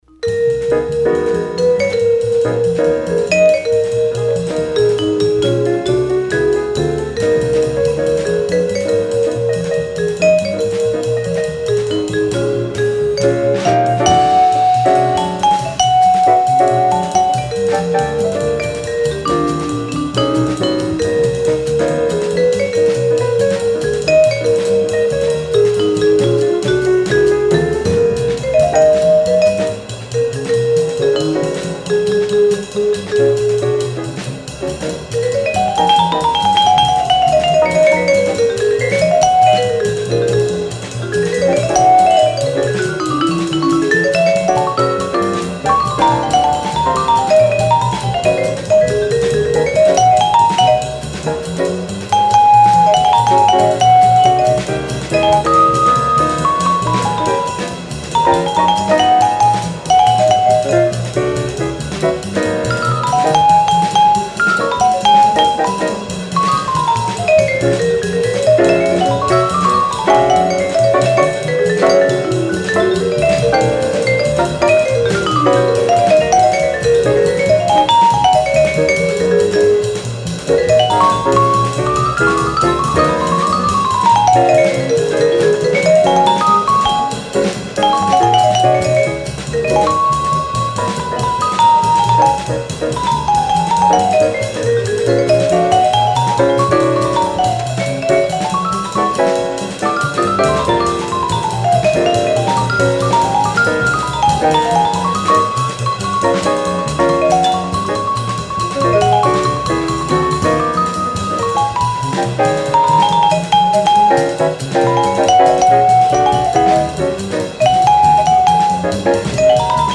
Second mono pressing